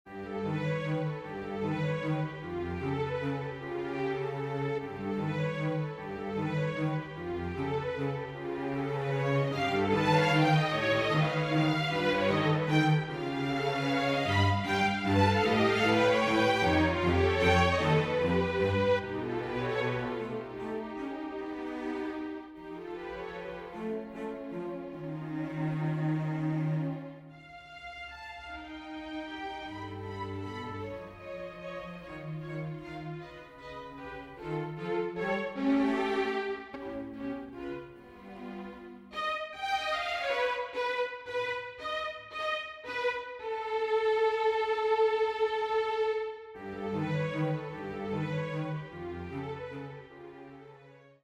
Drei Miniaturen für gemischte Streicher